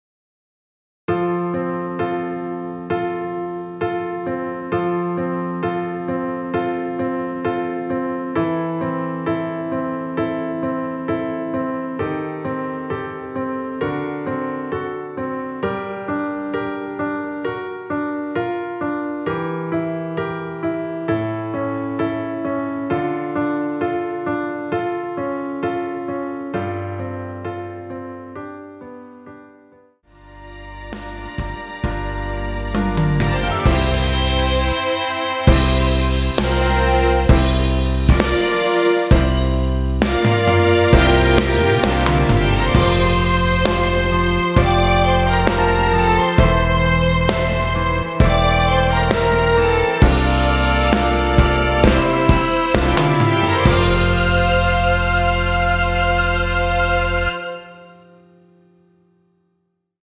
전주 없이 시작하는 곡이라 전주 1마디 만들어 놓았으며
원키에서(+5)올린 MR입니다.
앞부분30초, 뒷부분30초씩 편집해서 올려 드리고 있습니다.